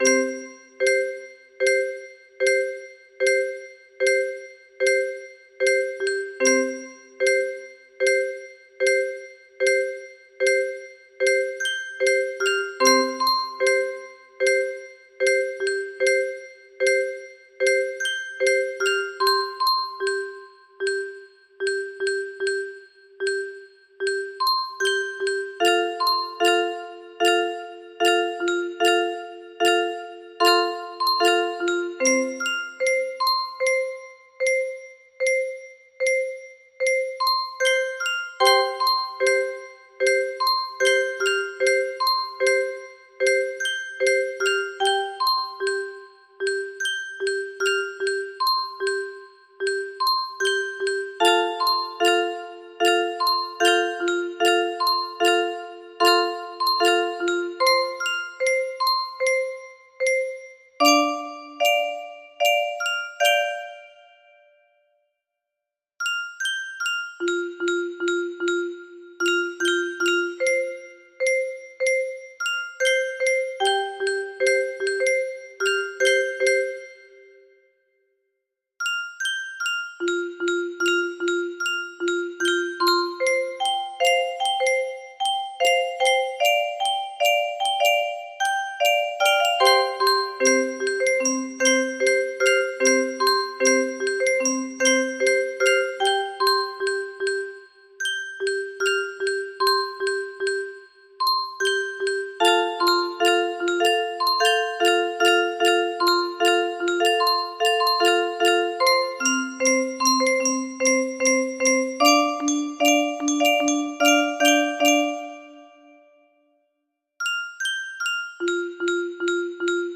Specially adapted for Muro Box 20